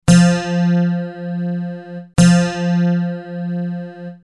Dropped C guitar tuning
Guitar string C - click to listen